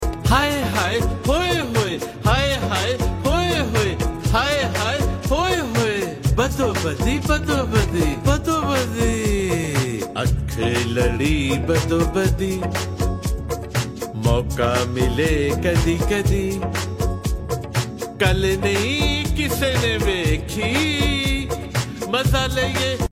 it’s a real toe-tapper.